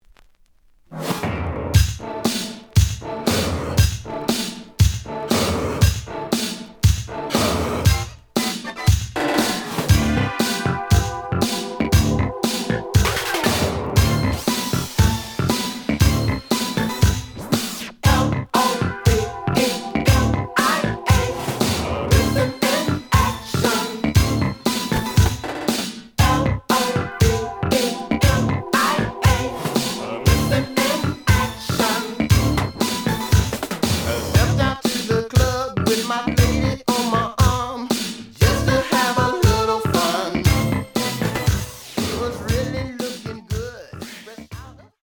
試聴は実際のレコードから録音しています。
The audio sample is recorded from the actual item.
●Genre: Funk, 80's / 90's Funk